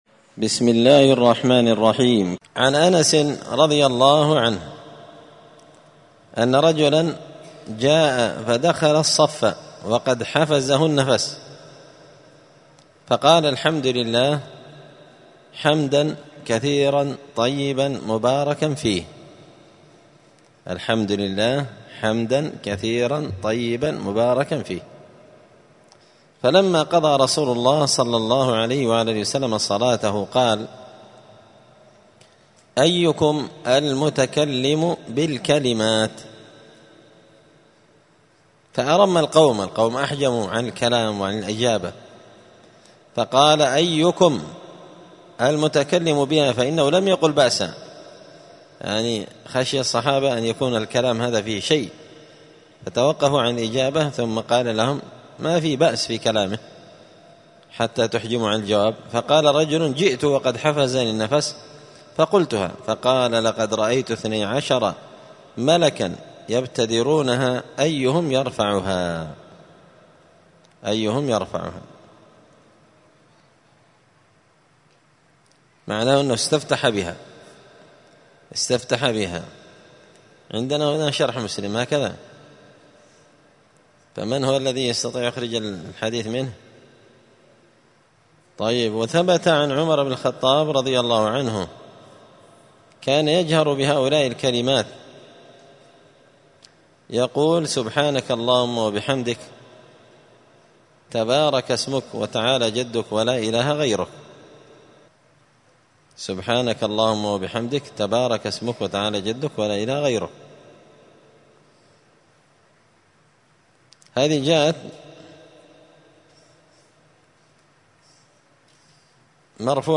*{الدرس الرابع والعشرون (24) أذكار الصلاة أصح دعاء لاستفتاح الصلاة}*